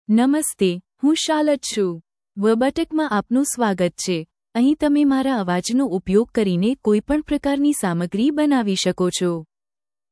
CharlotteFemale Gujarati AI voice
Charlotte is a female AI voice for Gujarati (India).
Voice sample
Listen to Charlotte's female Gujarati voice.
Charlotte delivers clear pronunciation with authentic India Gujarati intonation, making your content sound professionally produced.